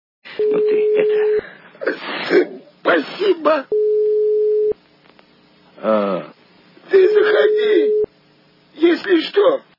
» Звуки » Из фильмов и телепередач » Ты это... - Заходи если, что...
При прослушивании Ты это... - Заходи если, что... качество понижено и присутствуют гудки.